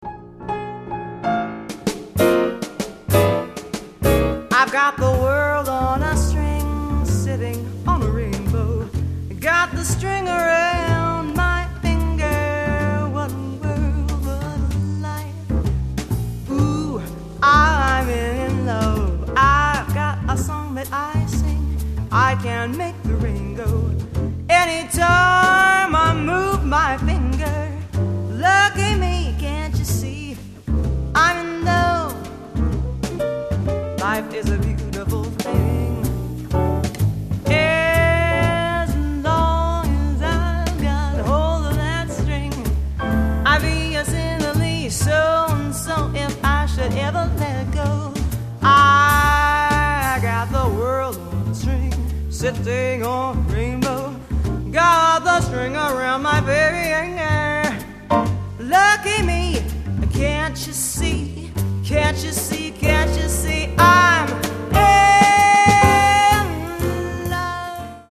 Popstar di classe, ma canta il jazz.
specie quando affronta brani più “swing”